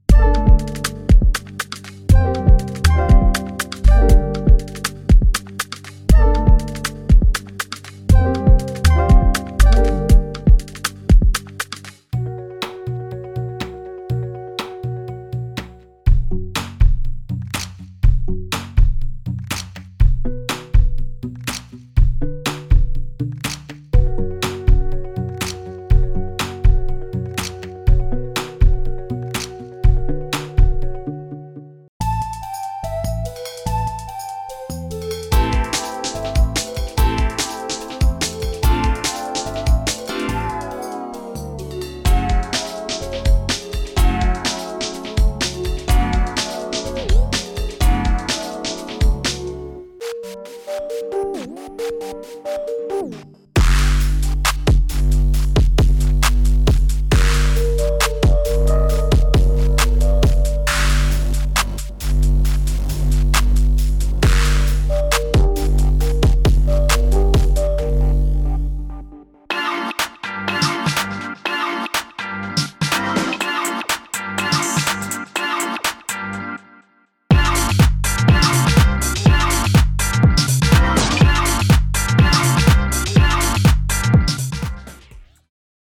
• 类型：Pattern 节奏音源 + 和弦生成器 + 多音色采样器
空灵铺底、梦幻琶音、环境音效，适合 Chillwave、Ambient、影视配乐。
复古未来感、00 年代流行 / 电子、闪亮合成器、复古鼓组，主打 Hyperpop、Retro。
低保真、实验性鼓组、不规则律动、Glitch 质感，适配 UK Bass、Trip-Hop、Lo-Fi。
• 风格：流行、嘻哈、电子、Chill、复古、影视配乐全能